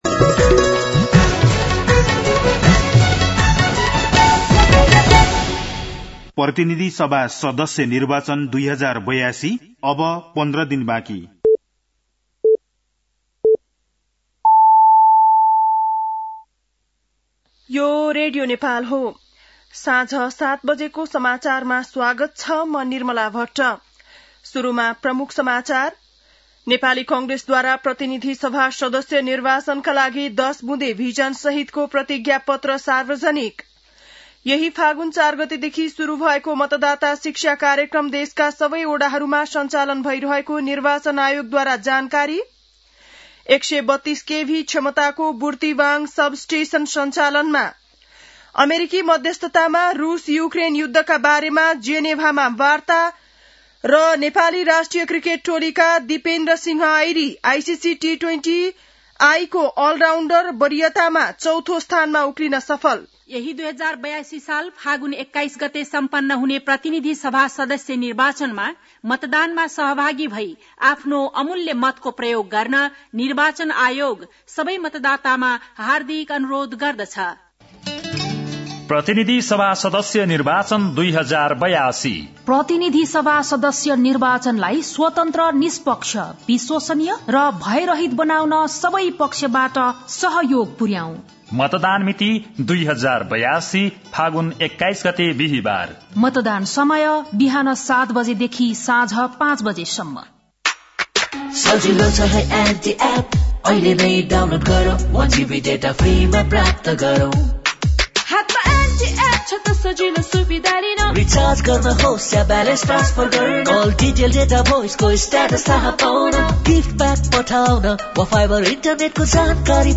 बेलुकी ७ बजेको नेपाली समाचार : ६ फागुन , २०८२